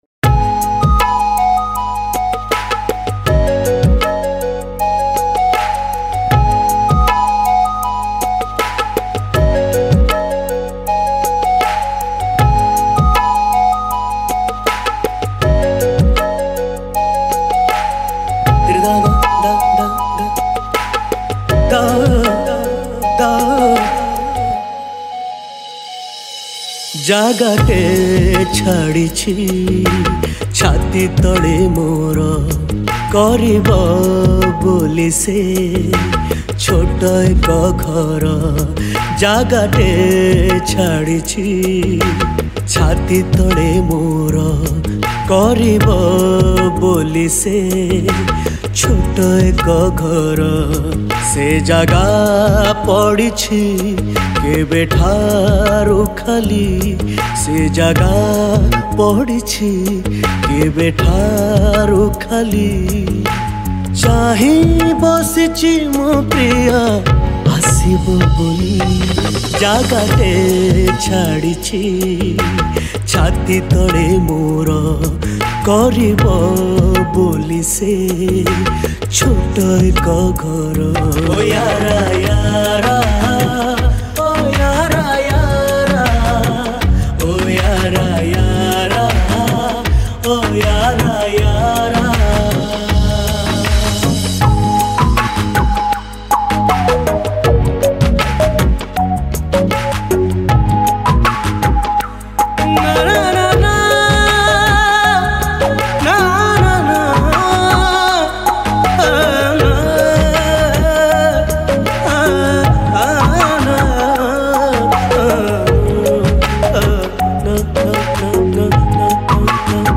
Odia Romantic Song